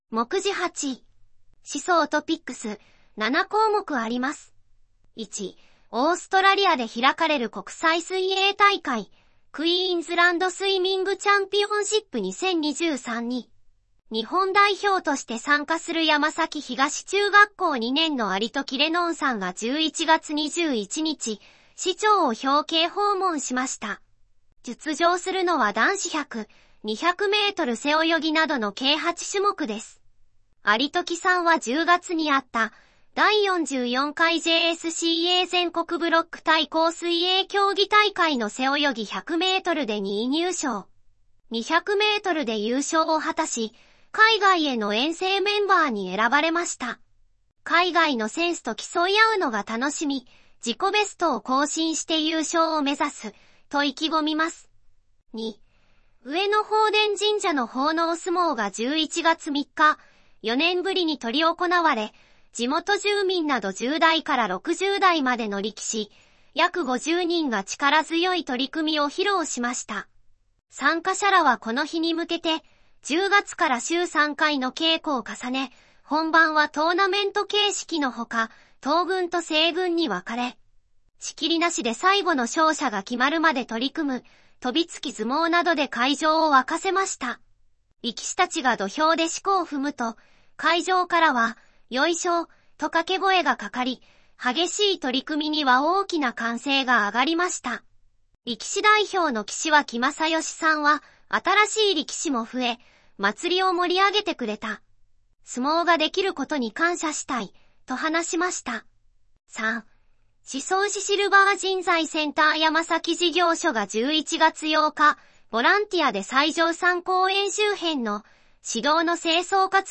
このページでは、広報しそうの記事を自動音声化した「声の広報」が楽しめます。自動音声変換ソフト「VOICEVOX」（ボイスボックス）で作成しています。